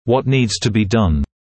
[wɔt niːdz tə biː dʌn][уот ниːдз тэ биː дан]что необходимо сделать